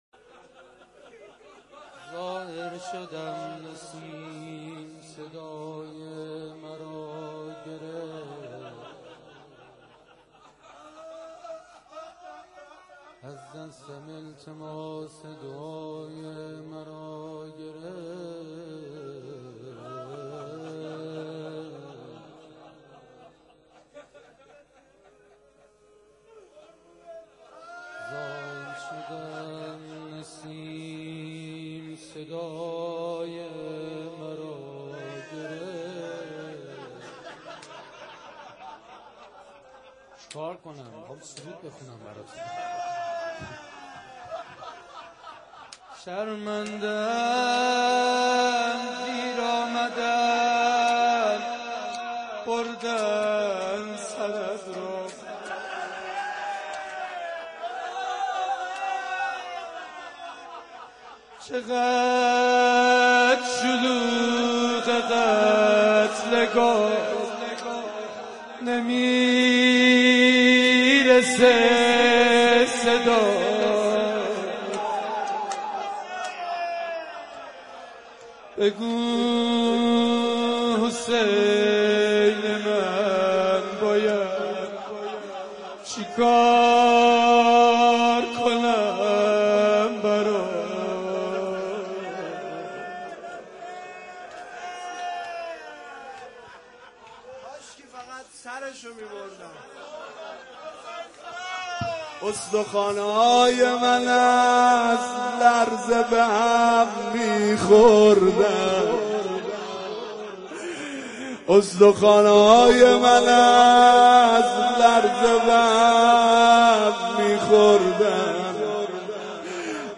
، 19 تیر 98، روضه هفتگی